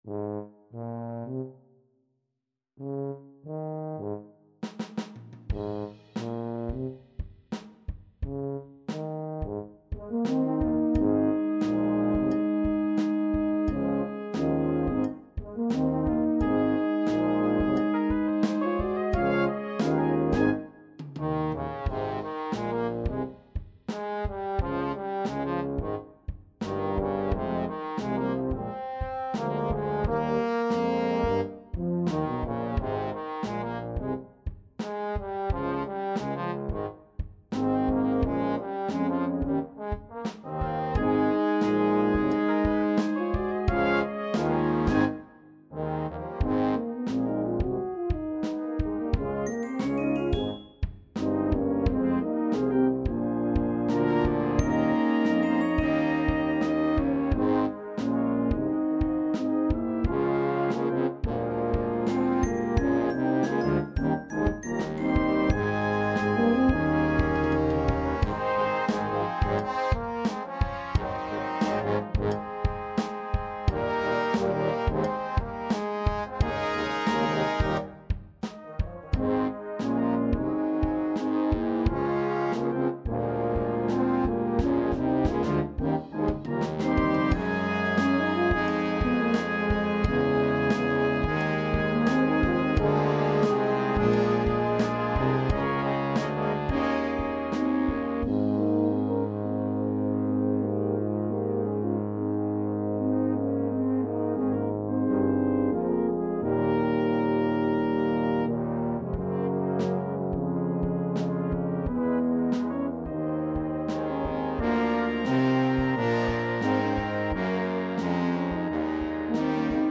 (Festival Arrangement)